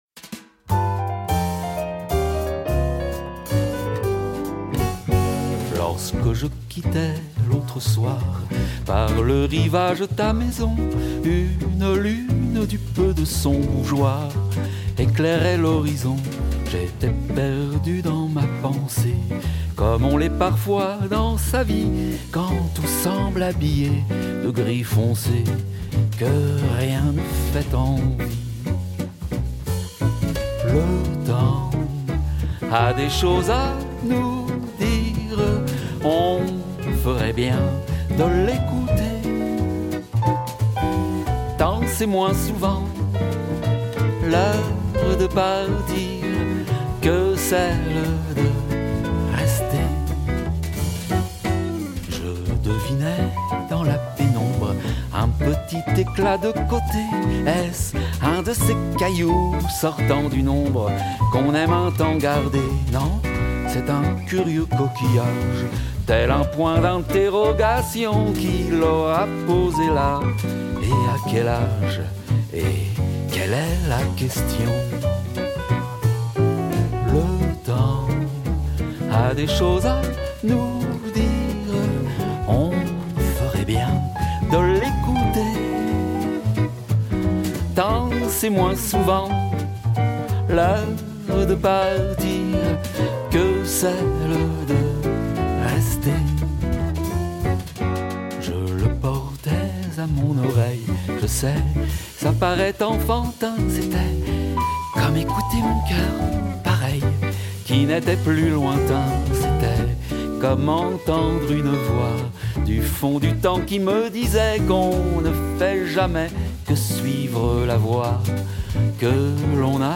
chant
piano
contrebasse
guitare et batterie